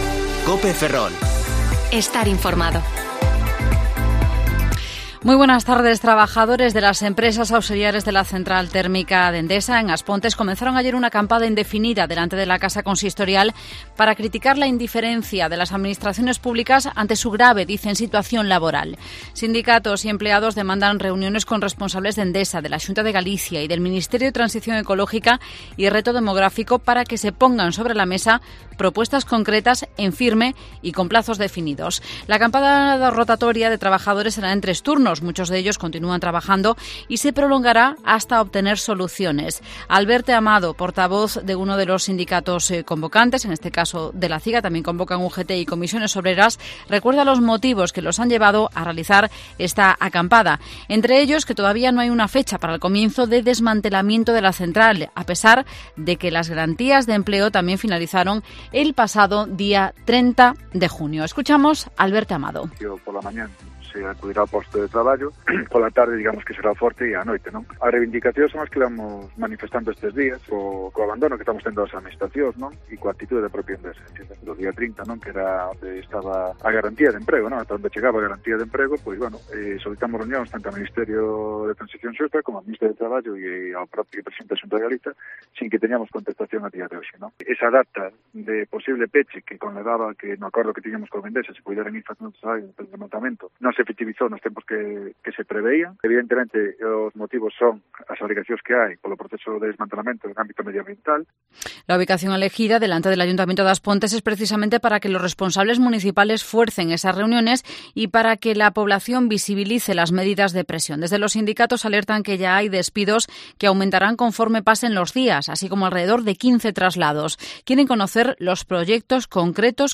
Informativo Mediodía COPE Ferrol 7/07/2021. De 14.20 a 14.30 horas